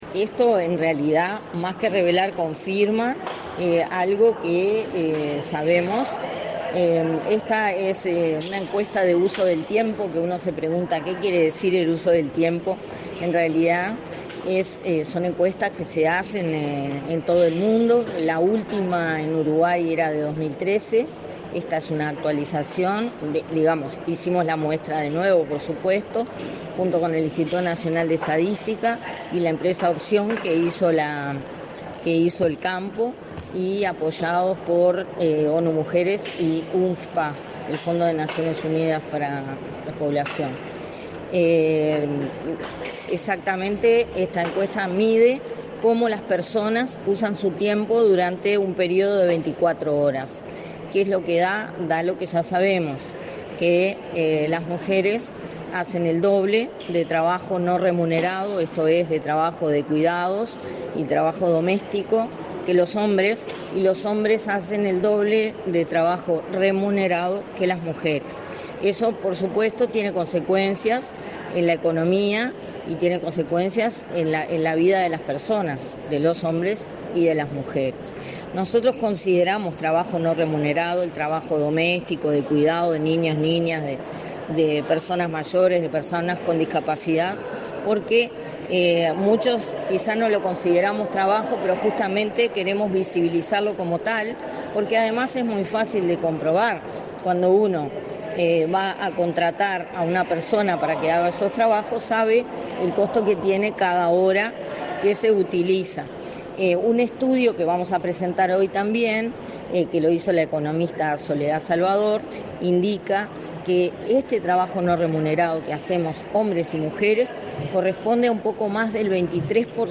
Declaraciones a la prensa de la directora del Inmujeres, Mónica Bottero | Presidencia Uruguay